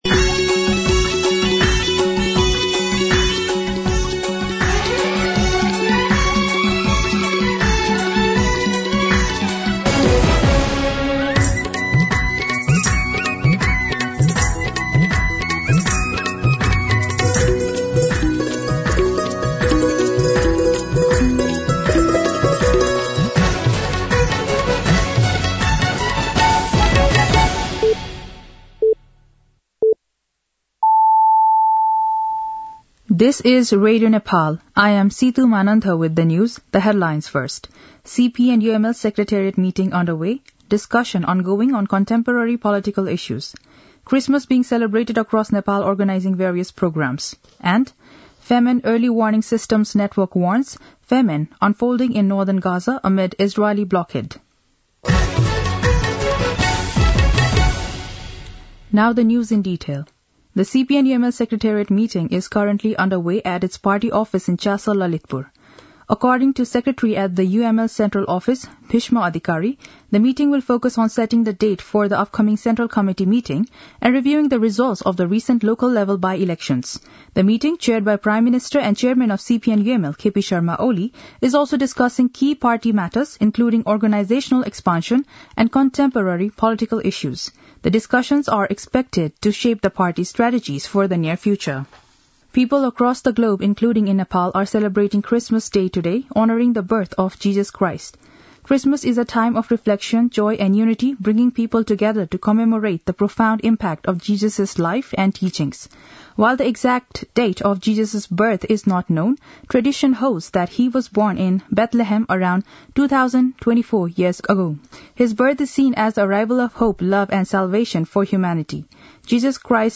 दिउँसो २ बजेको अङ्ग्रेजी समाचार : ११ पुष , २०८१
2-pm-English-News-.mp3